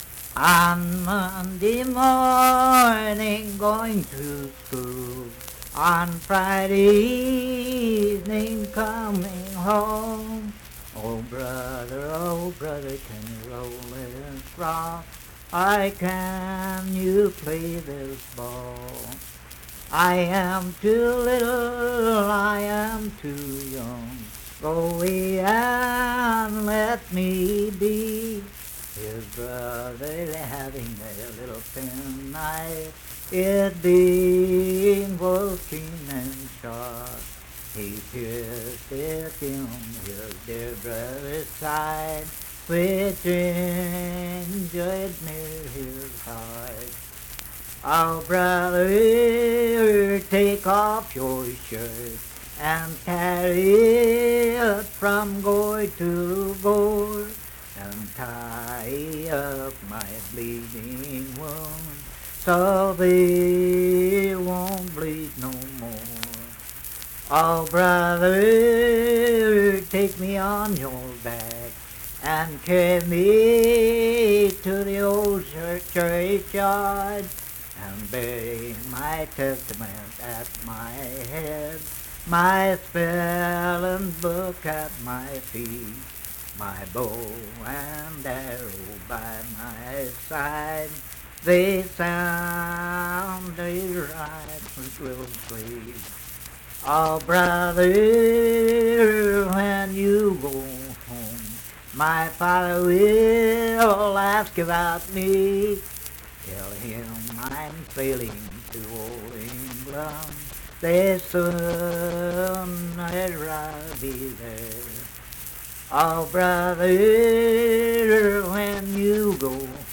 Unaccompanied vocal music
Verse-refrain 7(4).
Voice (sung)
Cabell County (W. Va.), Huntington (W. Va.)